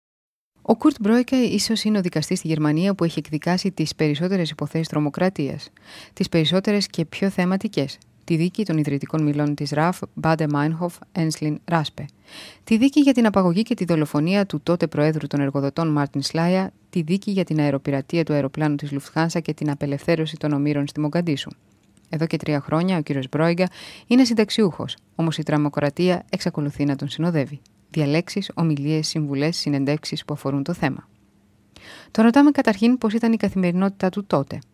griechische Sprecherin für Werbung, Dokumentationen, Voice Over, TV, Radio uvm.
Kein Dialekt
Sprechprobe: eLearning (Muttersprache):
greece female voice over artist